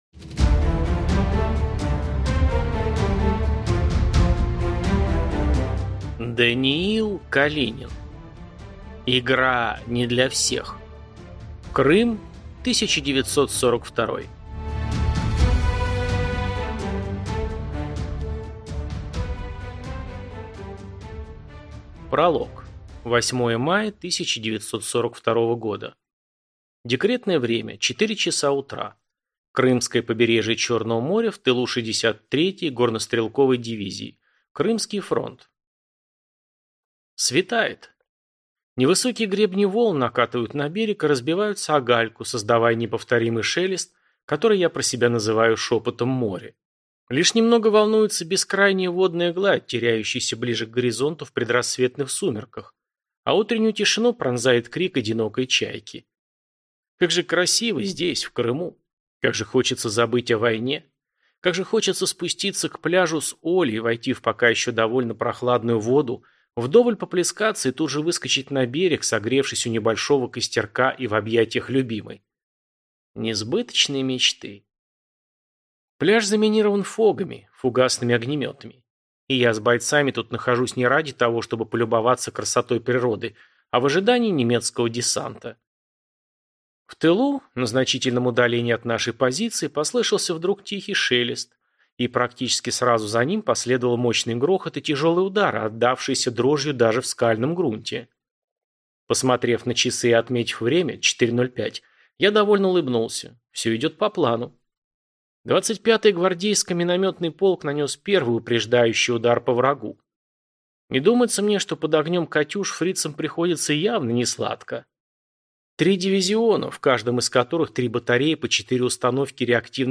Аудиокнига Игра не для всех. Крым 1942 | Библиотека аудиокниг